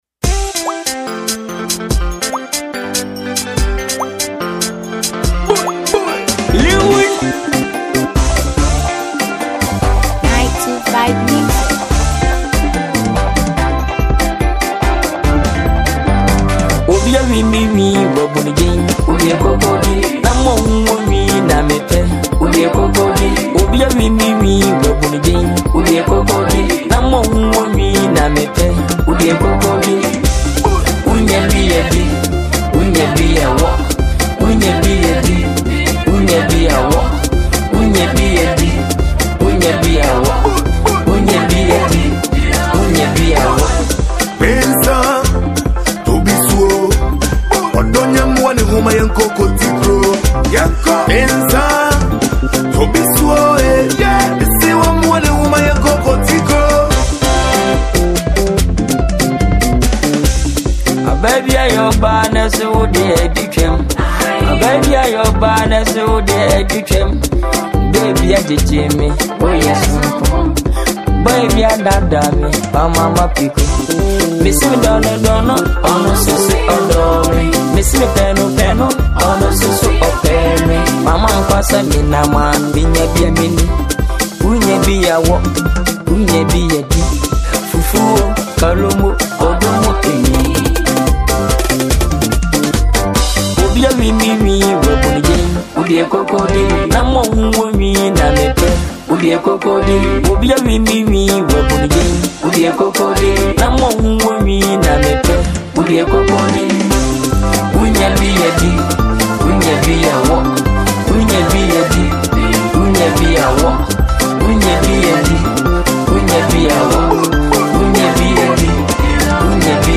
afrobeat single